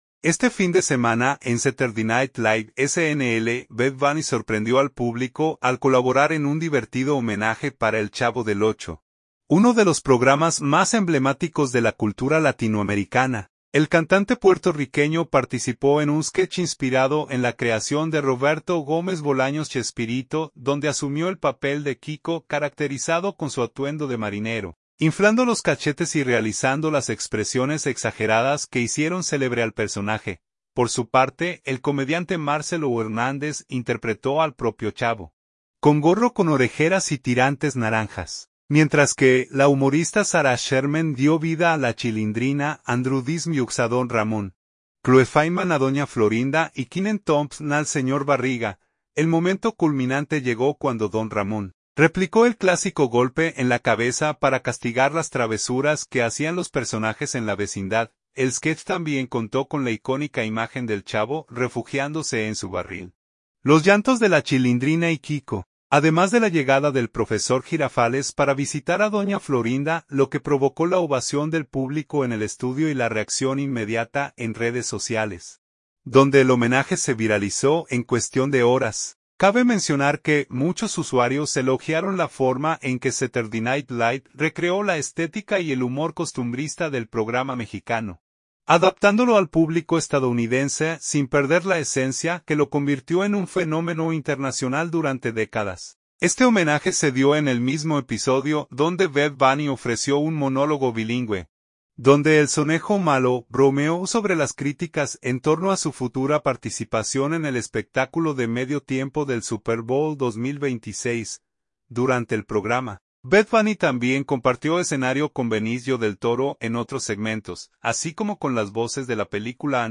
El cantante puertorriqueño participó en un sketch inspirado en la creación de Roberto Gómez Bolaños “Chespirito”, donde asumió el papel de Quico, caracterizado con su atuendo de marinero, inflando los cachetes y realizando las expresiones exageradas que hicieron célebre al personaje.
Por su parte, el comediante Marcello Hernández interpretó al propio Chavo, con gorro con orejeras y tirantes naranjas, mientras que la humorista Sarah Sherman dio vida a La Chilindrina, Andrew Dismukes a Don Ramón, Chloe Fineman a Doña Florinda y Kenan Thompson al Señor Barriga.
El sketch también contó con la icónica imagen del Chavo refugiándose en su barril, los llantos de La Chilindrina y Quico, además de la llegada del Profesor Jirafales para visitar a Doña Florinda, lo que provocó la ovación del público en el estudio y la reacción inmediata en redes sociales, donde el homenaje se viralizó en cuestión de horas.